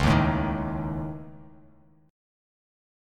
Dbsus2#5 chord